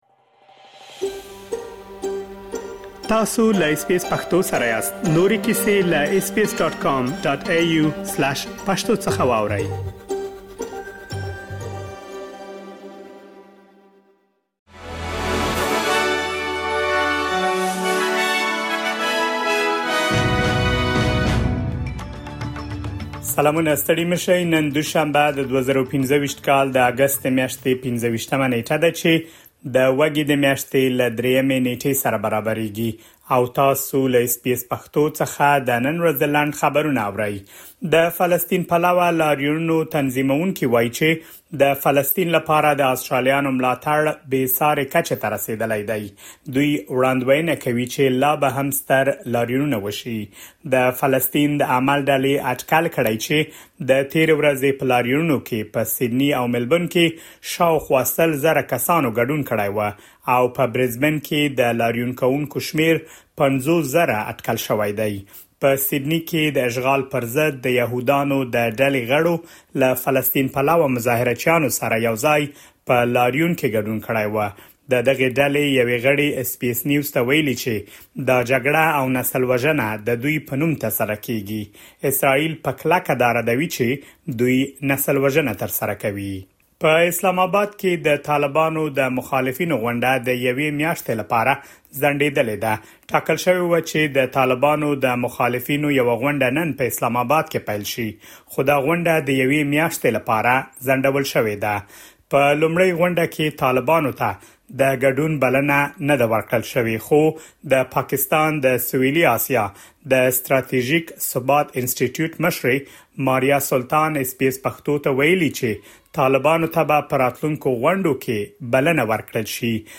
د اس بي اس پښتو د نن ورځې لنډ خبرونه |۲۵ اګسټ ۲۰۲۵